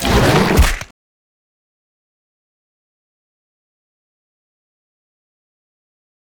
vpunch1.ogg